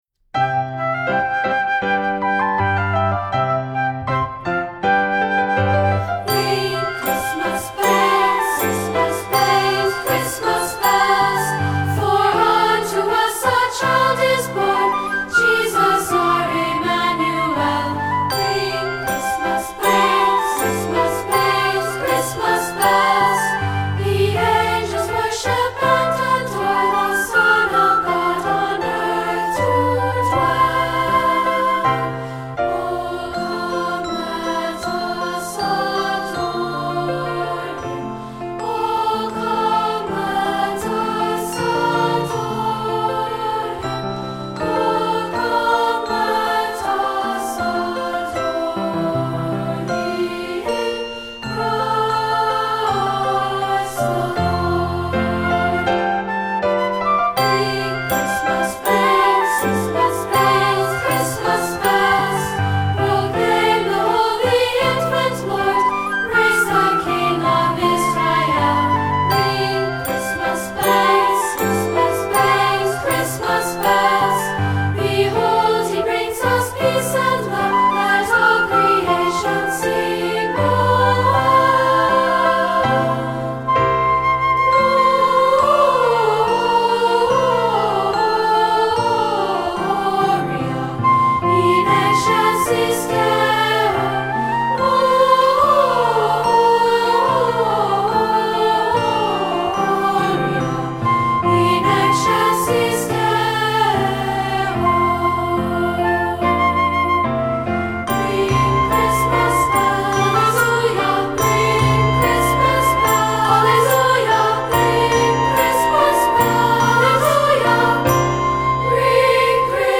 Voicing: SA and Piano